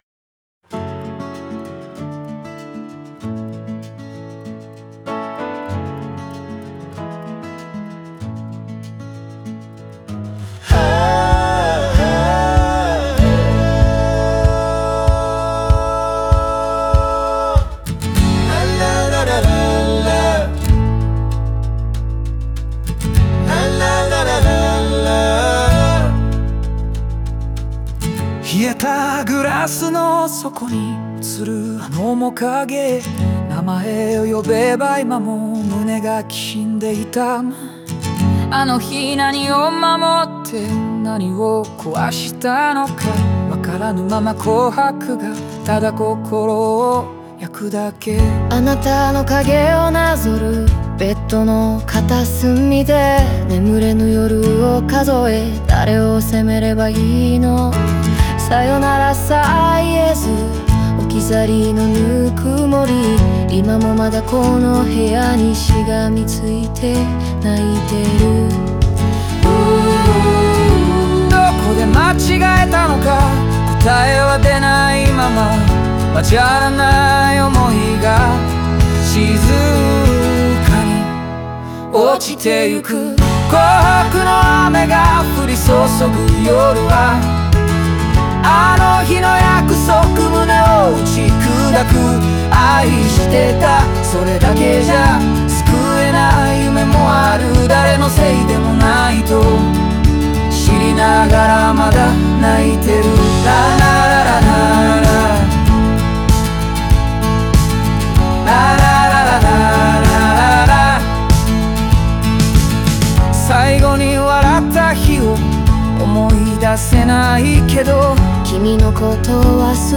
オリジナル曲♪
その余韻がゆっくり夜に溶けていく。
語りかけるようなギターの響きだけが
二人の思いをそっと見送るように静かに消えてゆく。